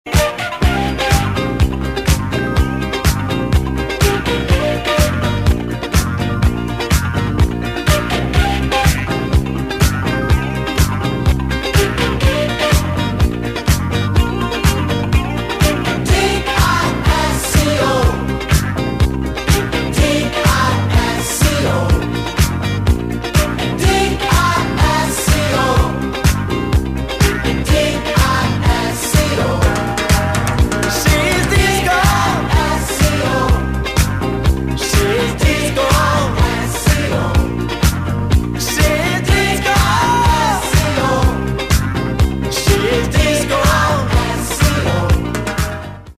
• Качество: 128, Stereo
мужской голос
dance
спокойные
танцевальные
дискотека 80-х